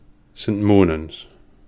St Monans (/ˈmnənz/
, locally /ˈsɪmənənz/ ),[2] sometimes spelt St Monance, is a village and parish in the East Neuk of Fife and is named after the legendary Saint Monan.